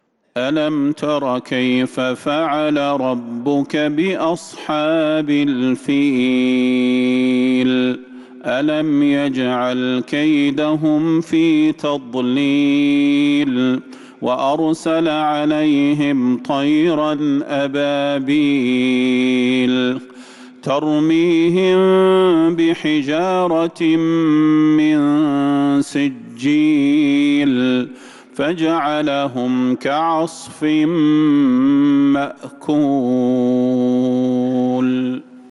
سورة الفيل | جمادى الأولى 1447هـ > السور المكتملة للشيخ صلاح البدير من الحرم النبوي 🕌 > السور المكتملة 🕌 > المزيد - تلاوات الحرمين